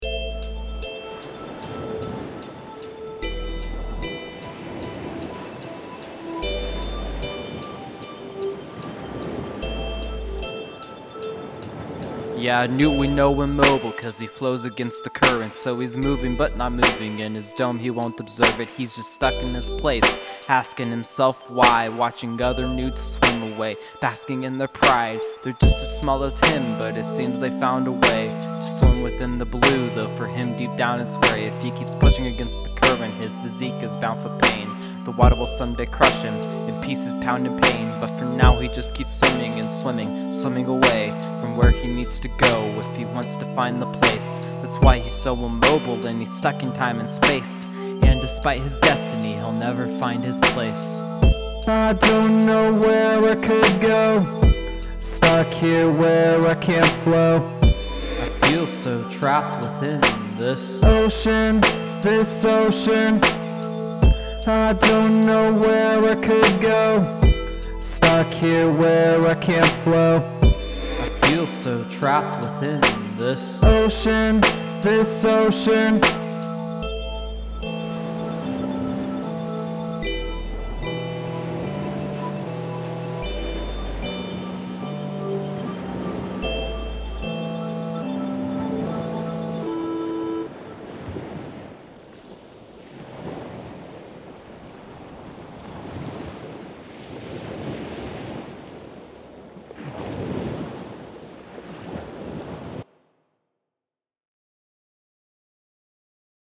The Ocean (An experimental hip-hop piece)
This is just a rough draft of a song that's going to be two verses when it's fully complete... mixing isn't as great as some peoples here but I really tried to make sure the vocals stood out in the mix and I like to experiment with different vocal effects and styles as well.
My vocal style is a sort of laid back almost monotone style, I suppose.
It isn't mastered (it's just a rough draft) so it's a bit quiet.